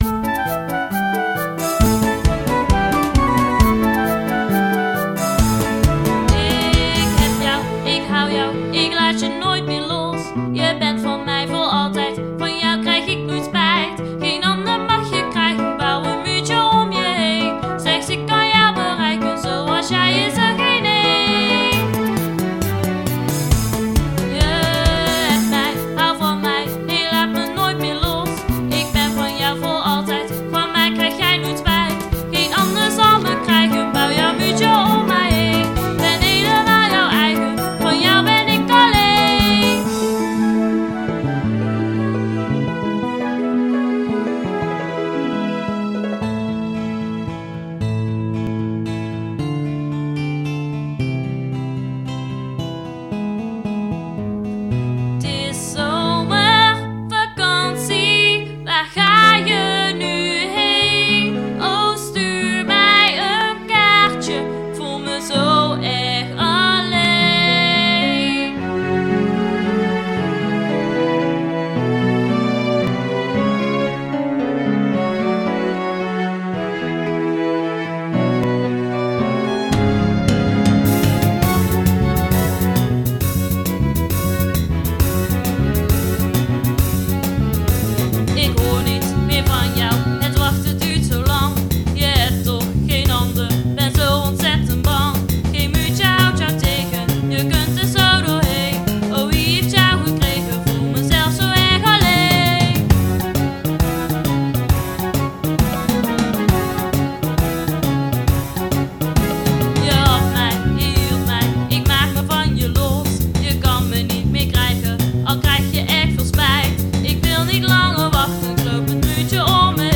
Nederlandstalig, kinderpop (in de trant van kinderen voor kinderen)